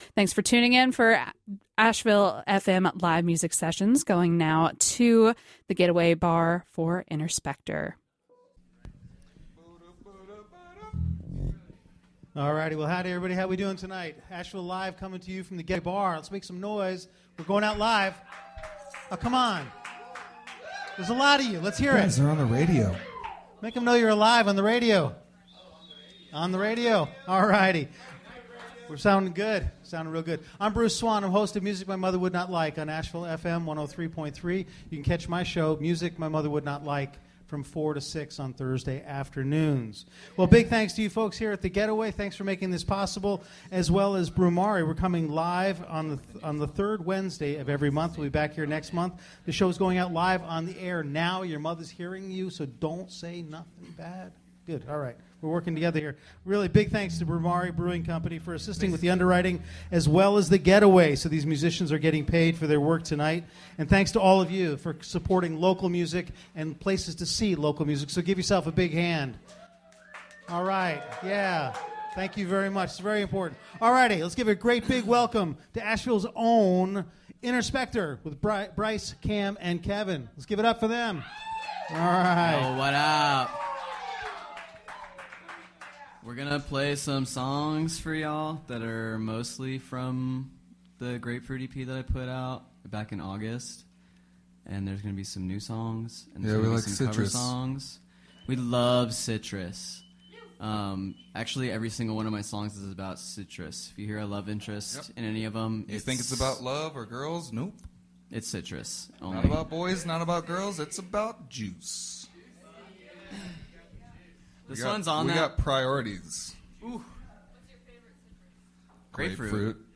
Live from The Getaway River Bar
Recorded during safe harbor – Explicit language warning